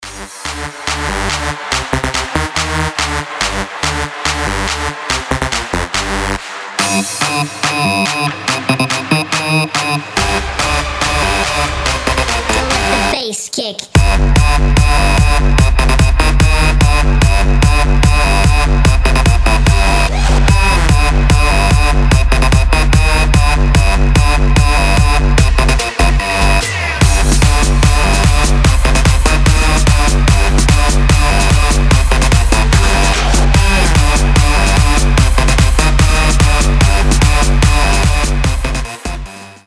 • Качество: 320, Stereo
мощные
энергичные
быстрые
electro house
Хардбасс
очень громкие
Стиль: electro house, hard bass